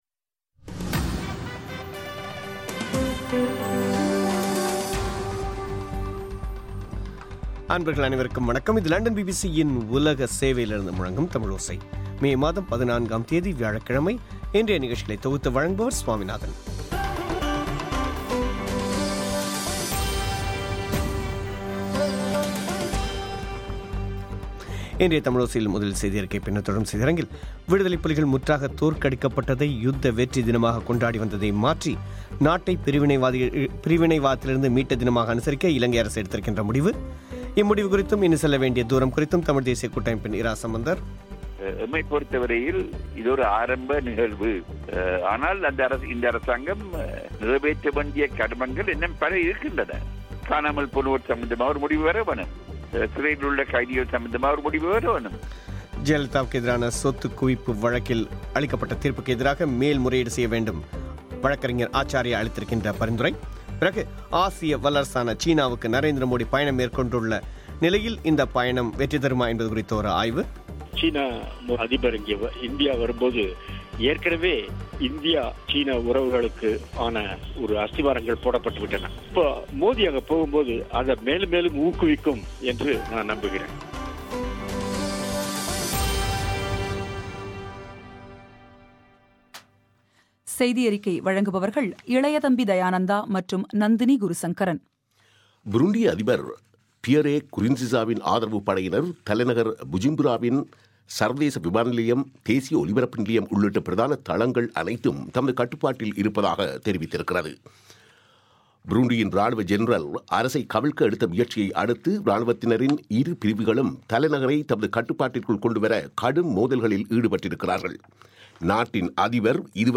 முக்கியச் செய்திகள்